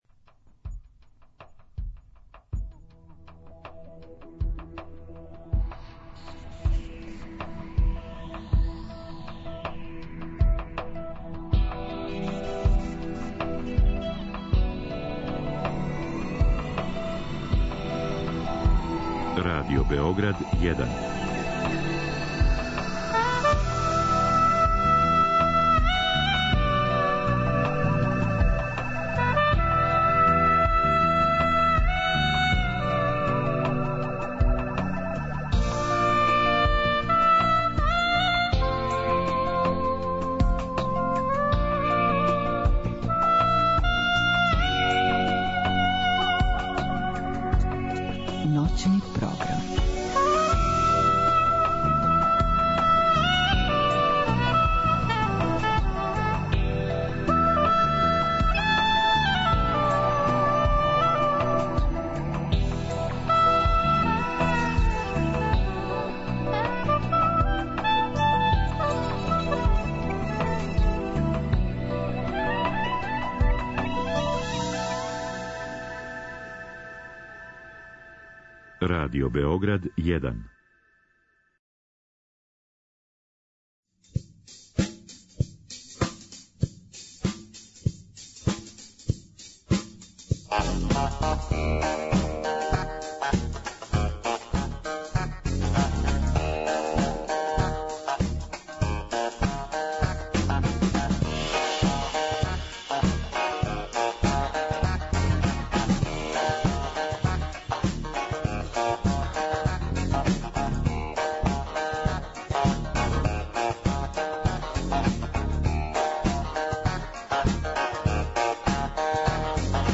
Наставља се сарадња између емисија Друга Страна Рачунара (радио Београд 1) и Лет изнад Лукавичјег гнезда (Радио Источно Сарајево). Због тога су вечерас са нама у студију драги гости...